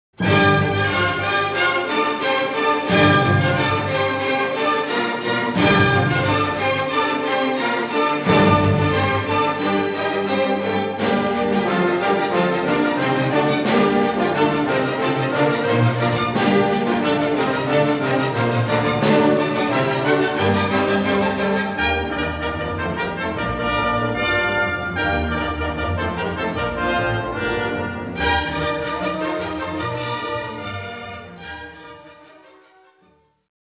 piano
violin
viola
cello
five pieces for orchestra Op.32/b
for piano and orchestra Op.25
for string trio Op.10